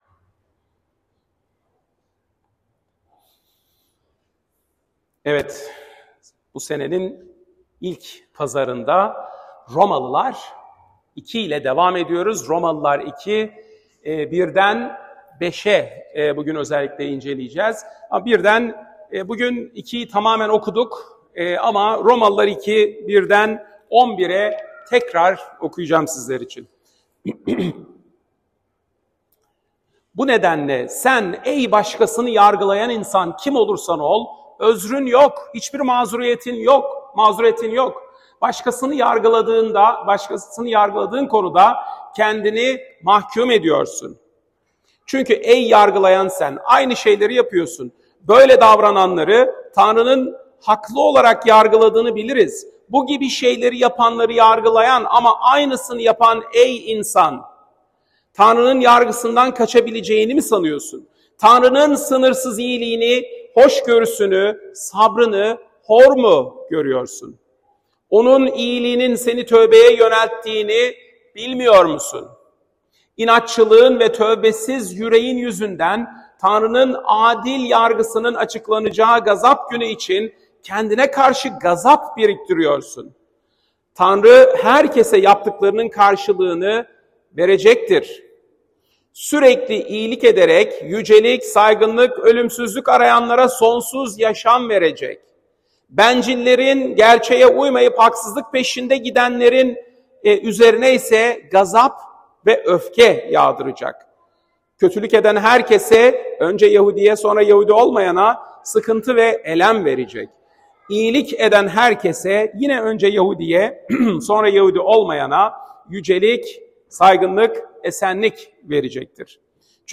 Salı, 1 Nisan 2025 | Romalılar Vaaz Serisi 2024-26, Vaazlar